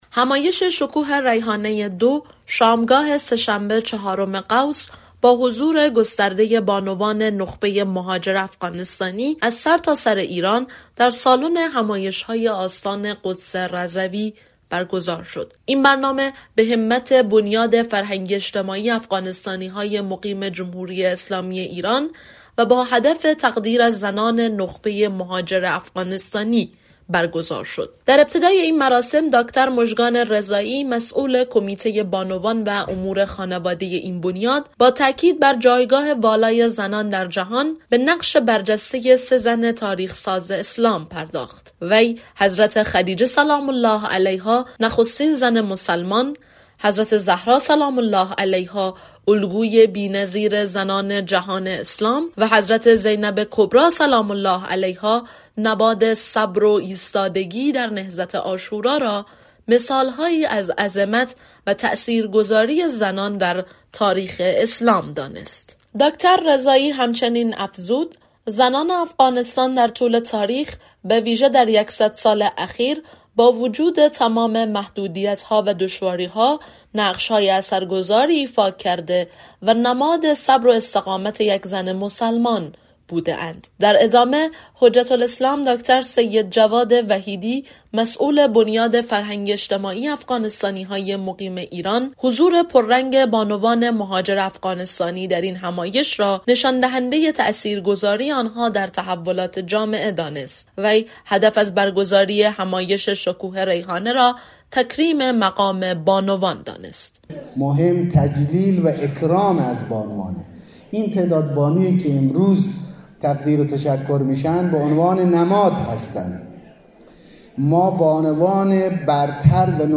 خبر